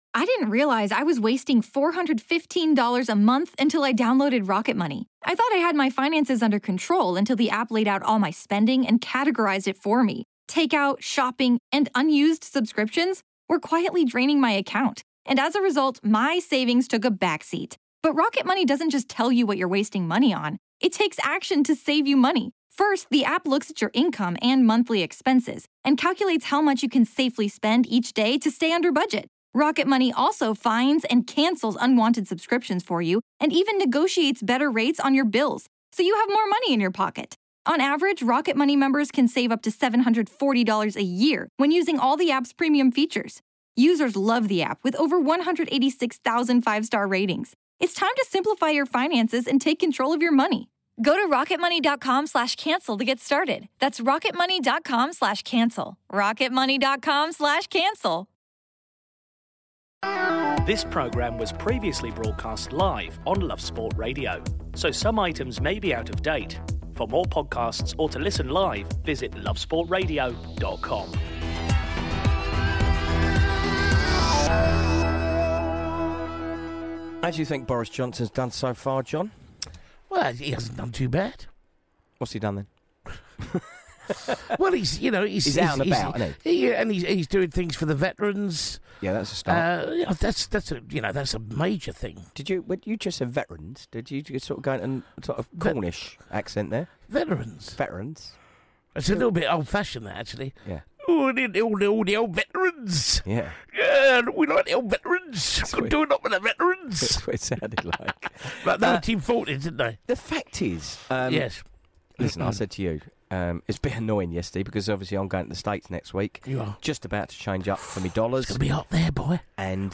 Plus, Boris and Sturgeon's icy relationship, Eddie Hearn's double-standards, and Vicki Michelle calls in...